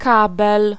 Ääntäminen
Synonyymit télédistribution Ääntäminen France: IPA: [kabl] Tuntematon aksentti: IPA: /kɑbl/ Haettu sana löytyi näillä lähdekielillä: ranska Käännös Ääninäyte Substantiivit 1. kabel {m} Suku: m .